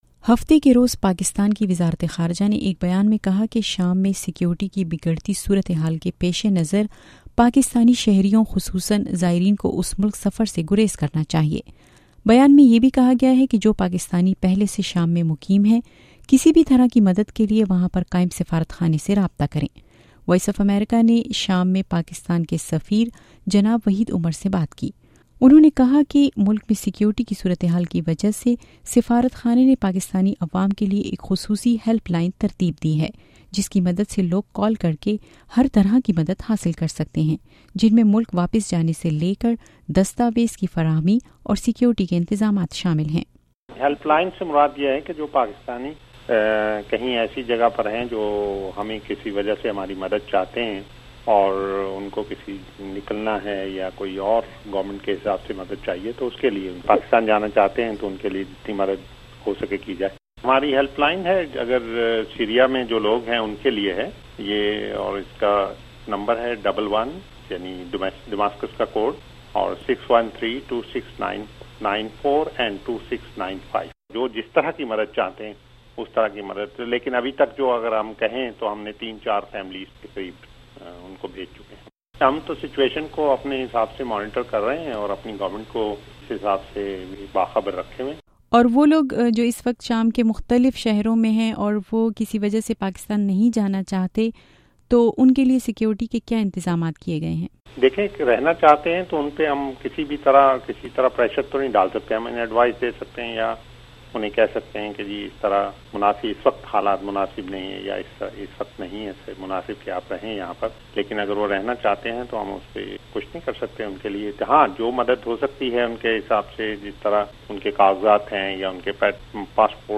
Interview with Pakistani Ambassador to Syria